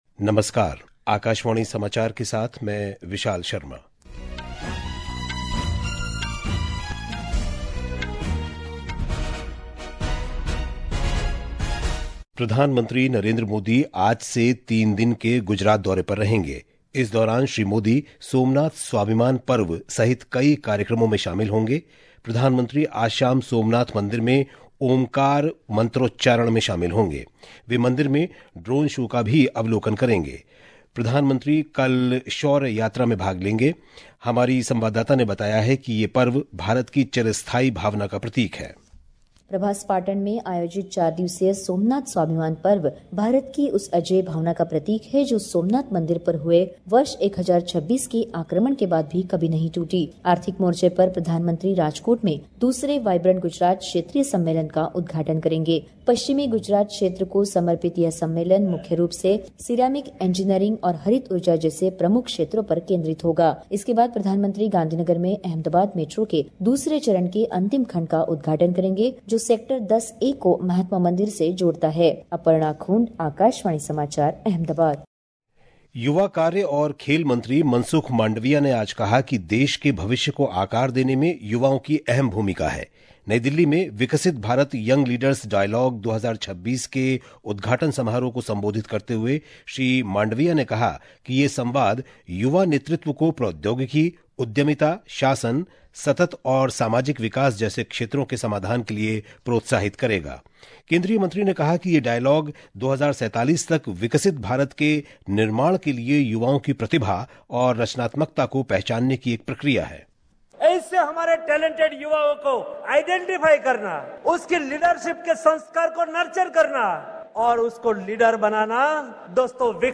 National Bulletins
प्रति घंटा समाचार | Hindi